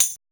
LINN TAMB.wav